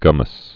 (gŭməs) also gum·mose (-ōs)